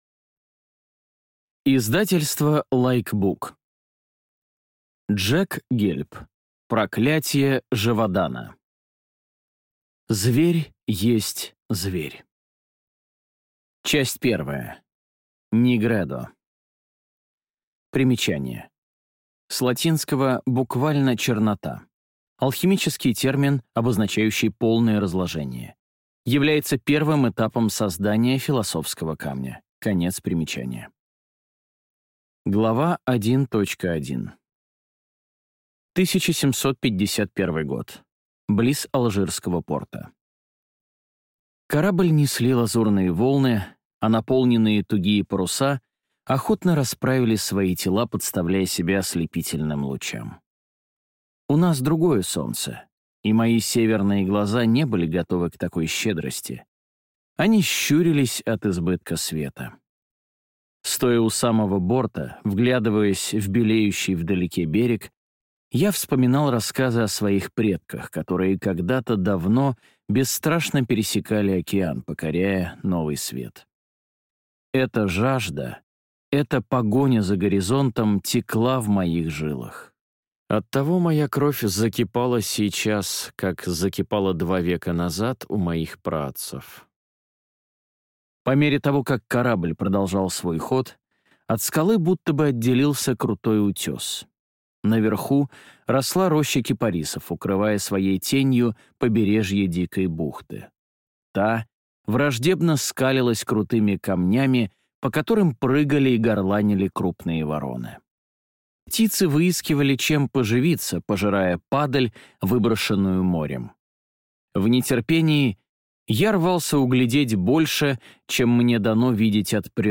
Аудиокнига Проклятье Жеводана | Библиотека аудиокниг